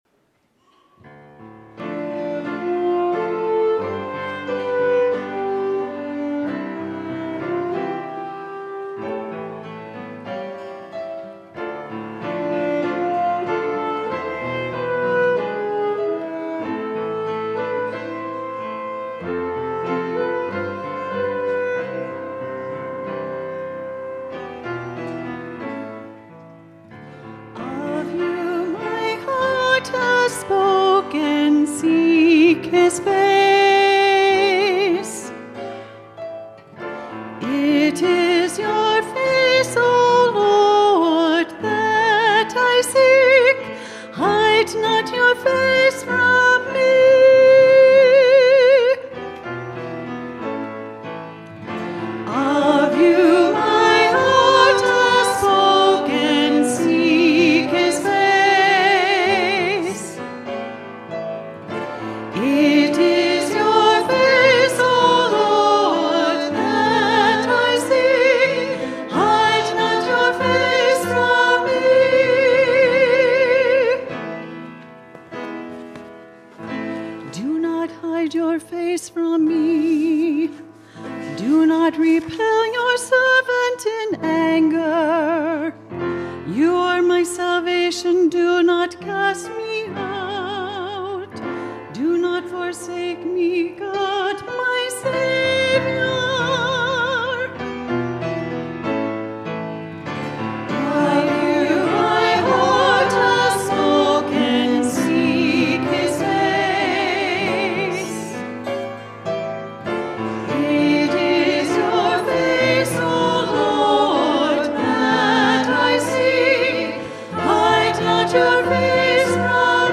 Lent-II-Introit-OF-YOU-MY-HEART-HAS-SPOKEN.mp3